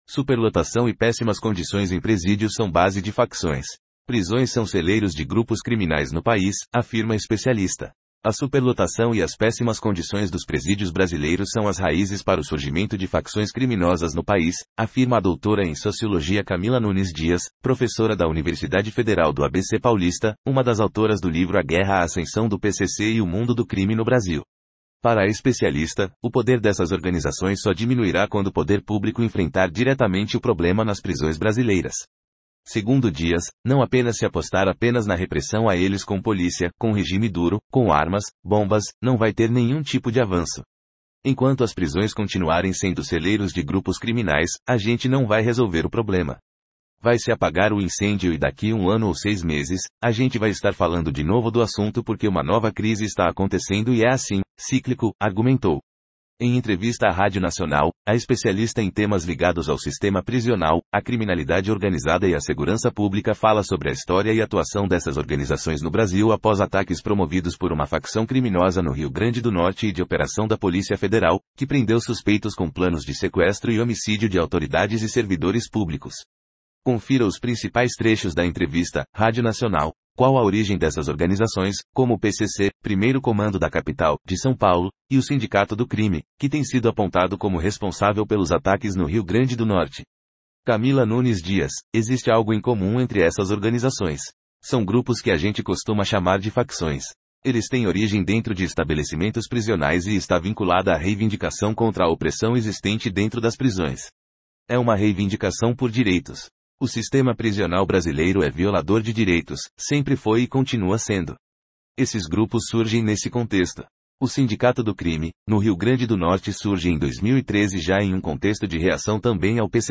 Confira os principais trechos da entrevista: